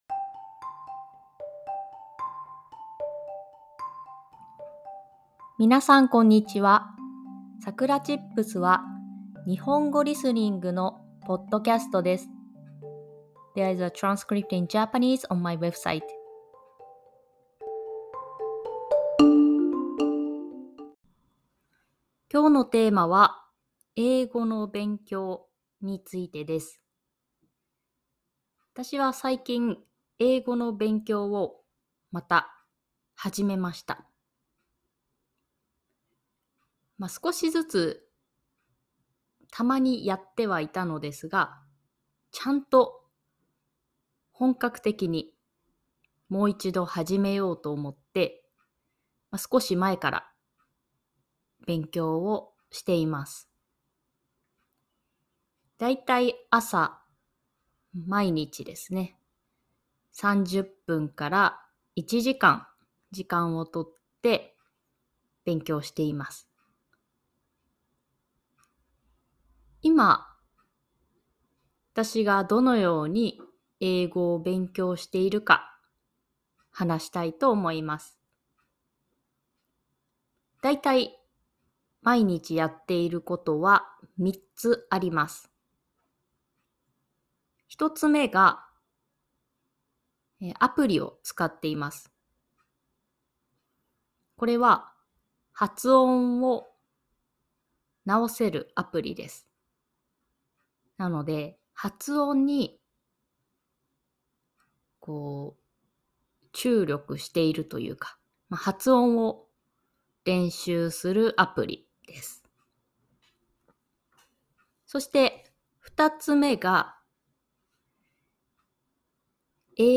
Japanese Listening｜611. 英語の勉強 Learning English